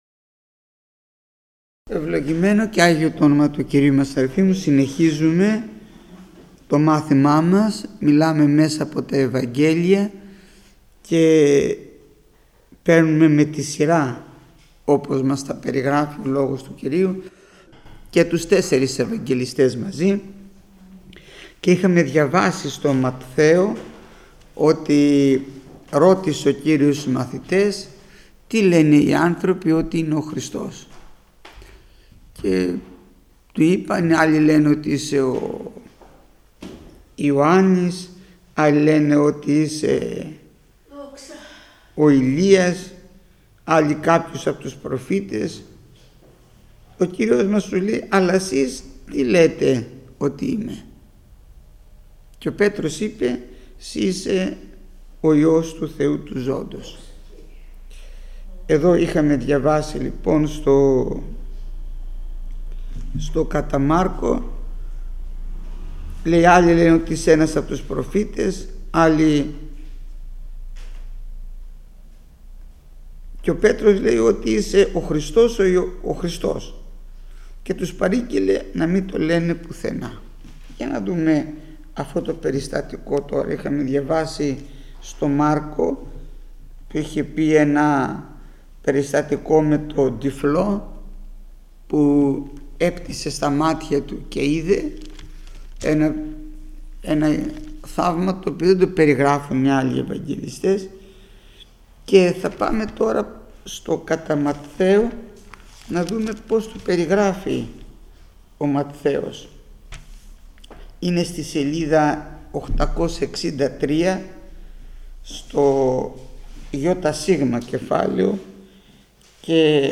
Μάθημα 458ο Γεννηθήτω το θέλημά σου